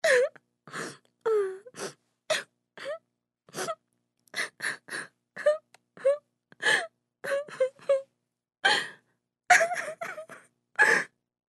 Звуки слёз
Звук: тихий плач скромной девушки